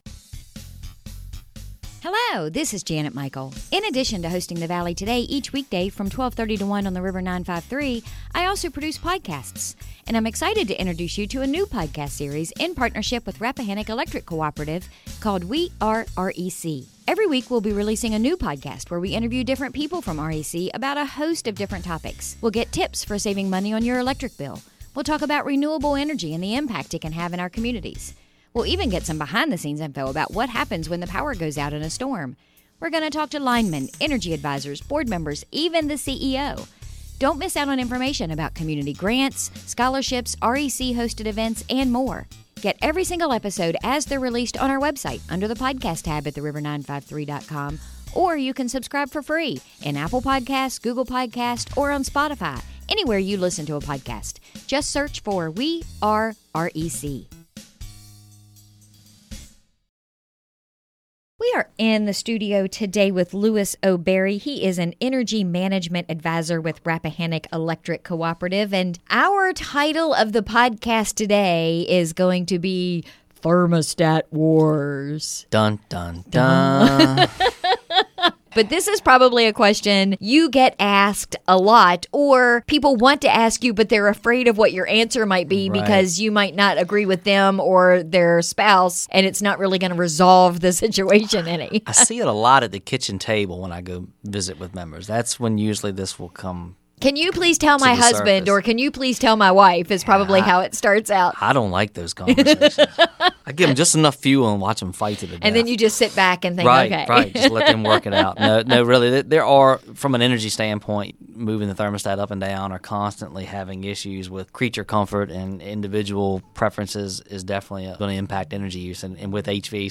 Our conversation today